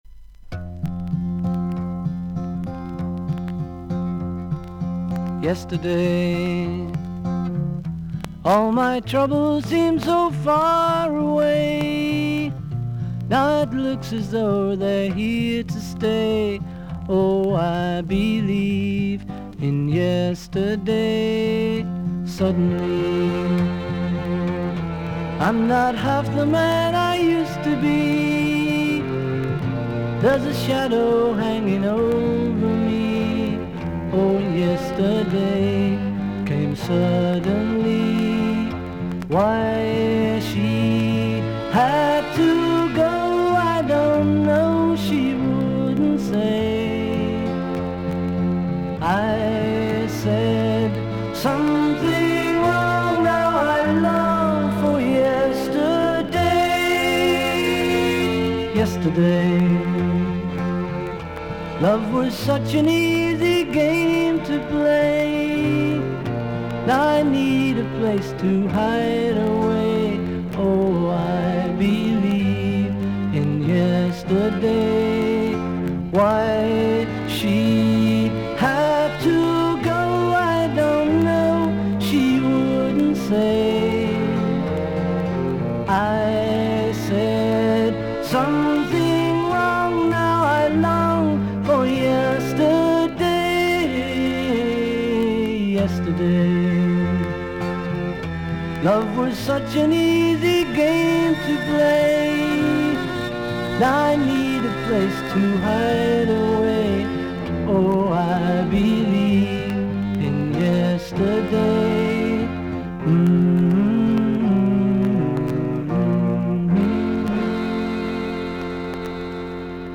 音のグレードはVG+〜VG++:少々軽いパチノイズの箇所あり。少々サーフィス・ノイズあり。クリアな音です。